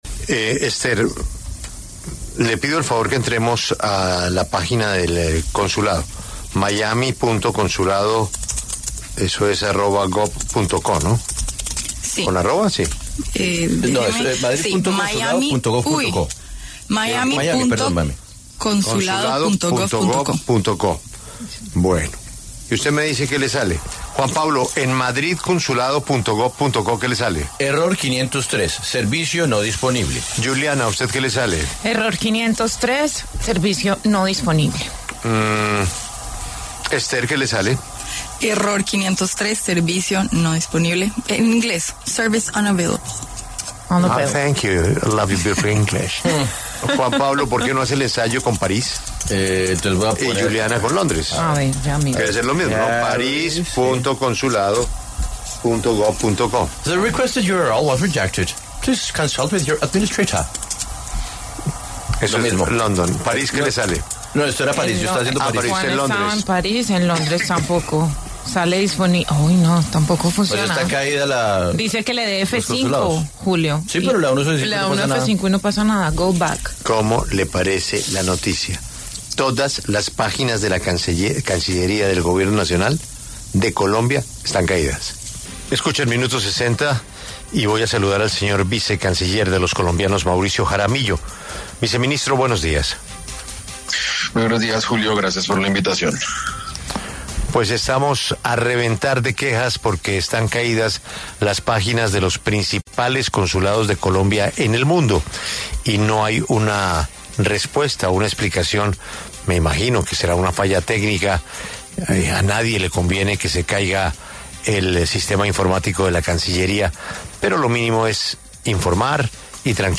El vicecanciller Mauricio Jaramillo habló con La W y aseguró que son fallas técnicas que ya se estaban resolviendo.